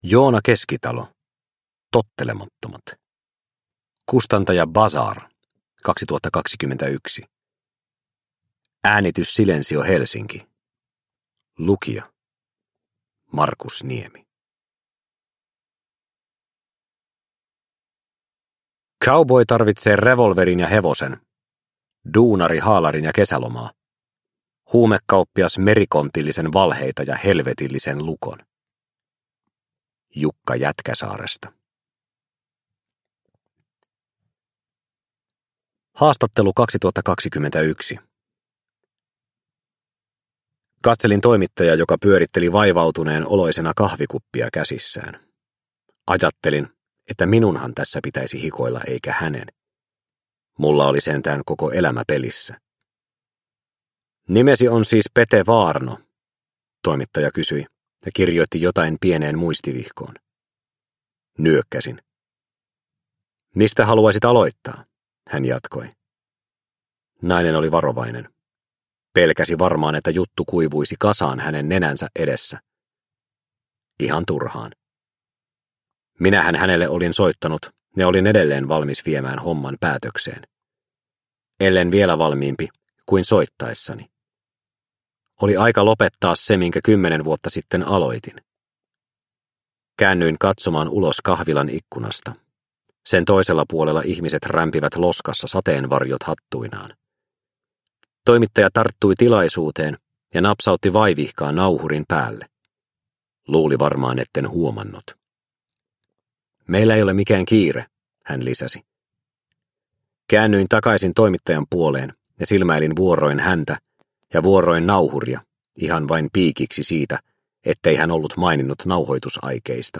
Tottelemattomat – Ljudbok – Laddas ner